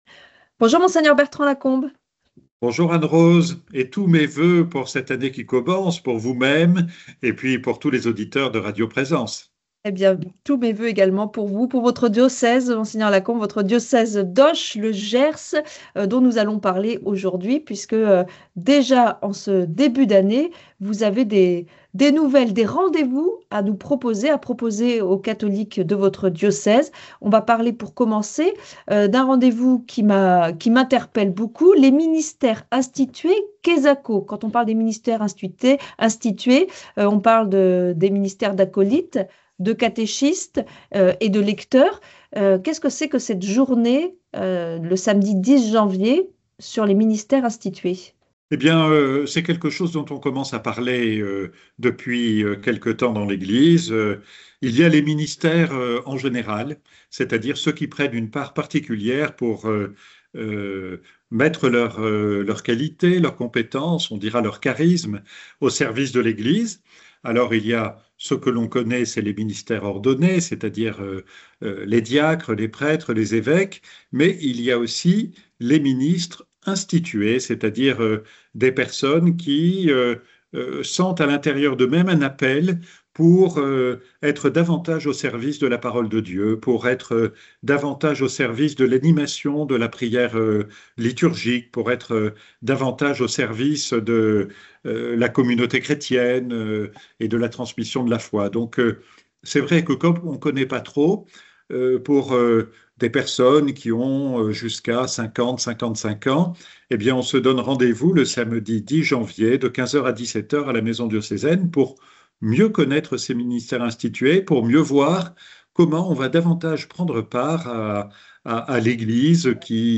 L’actualité du diocèse d’Auch présentée par Monseigneur Bertrand Lacombe, archevêque du diocèse du Gers. Cloture de l’année sainte, journée de formation sur les ministères ordonnés, etc.
Le grand entretien